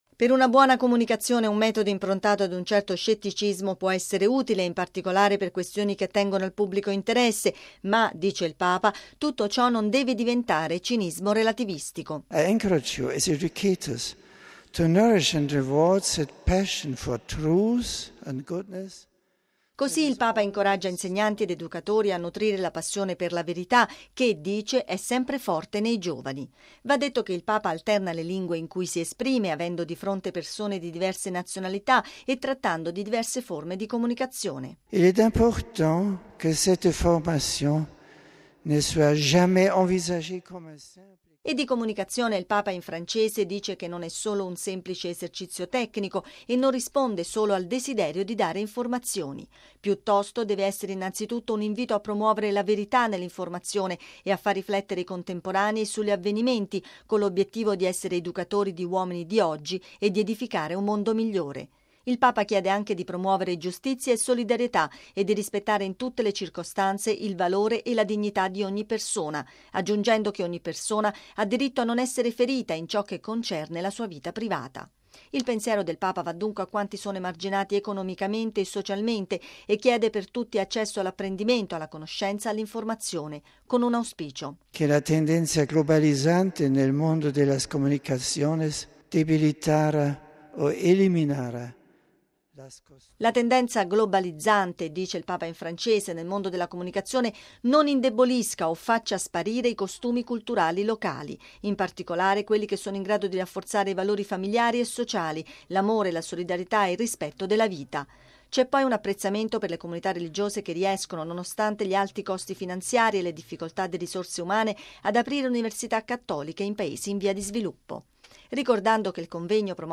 Va detto che il Pontefice alterna le lingue in cui si esprime avendo di fronte persone di diverse nazionalità e trattando di diverse forme di comunicazione.